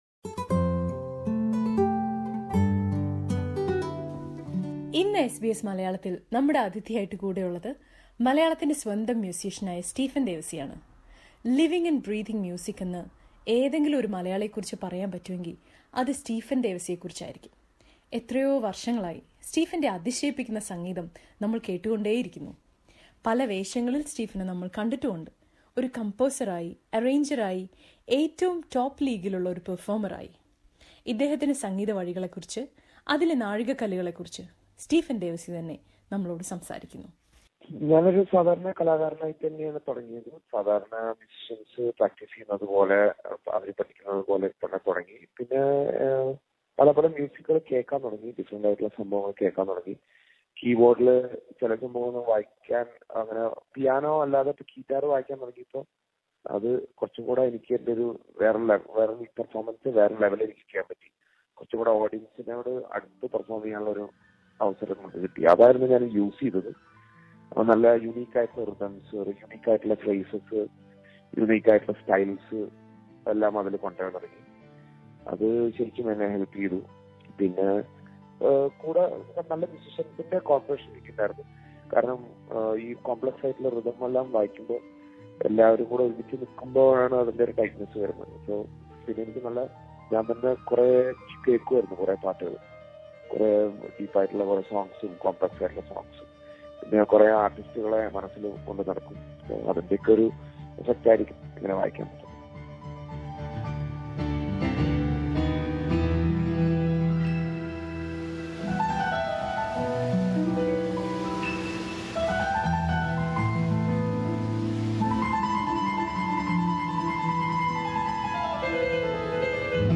Stephen Devassy speaks about his journey as a musician